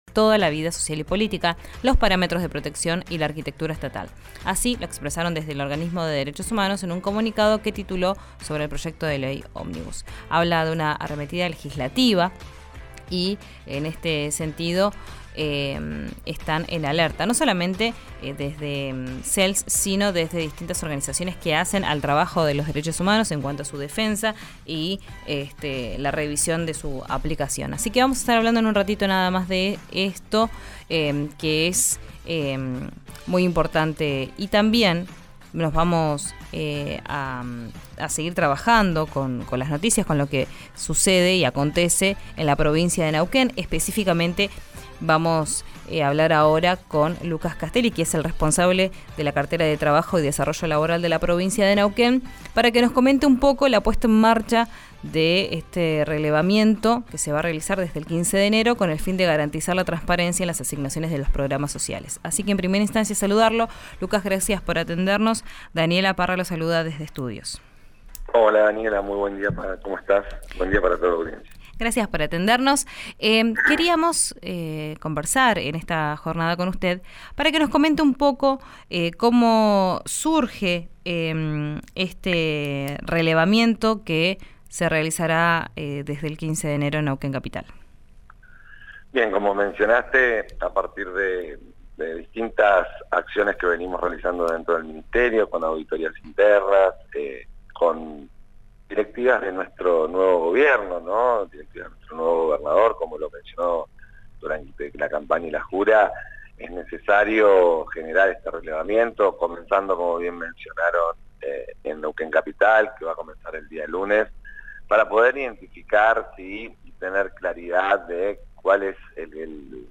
En diálogo con RIO NEGRO RADIO explicó los alcances del relevamiento a beneficiarios de planes sociales que comenzará la semana próxima.